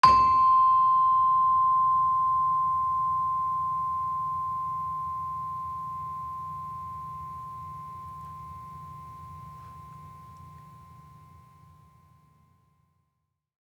HSS-Gamelan-1